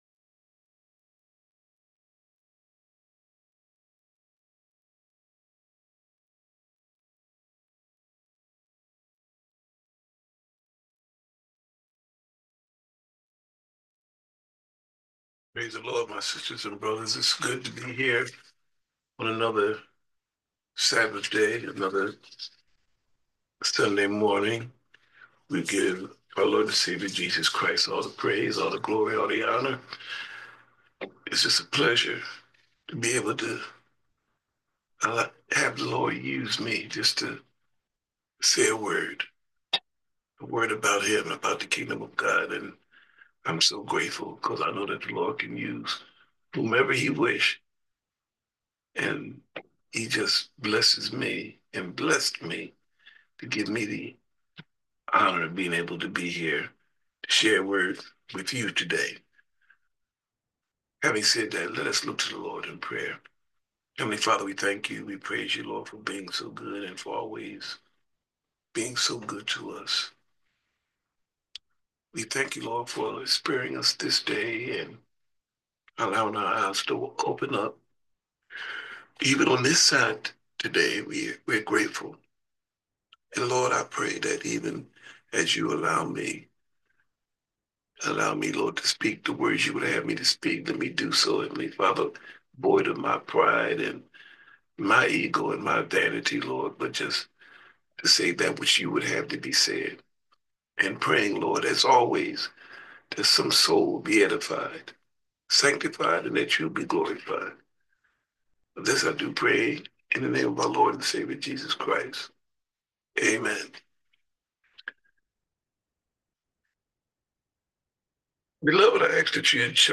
REAL TALK Sermon - St James Missionary Baptist Church